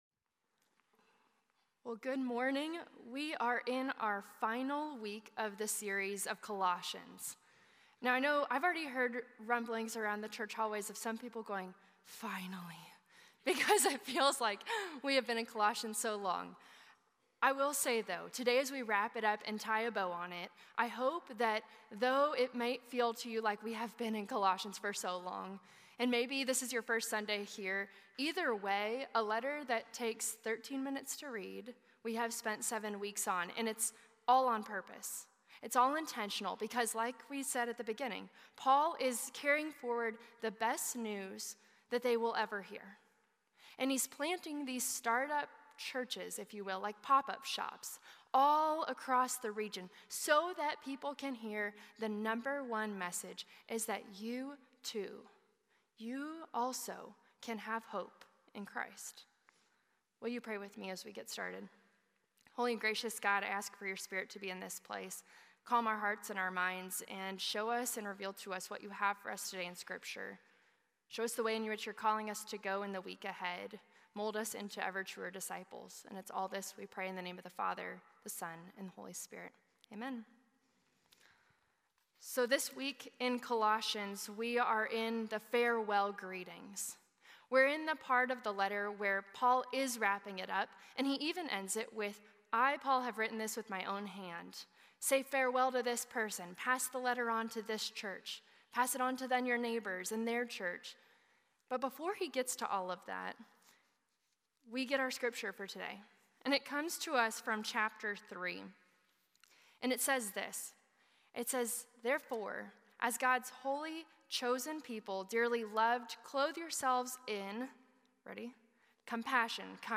11:00 AM Traditional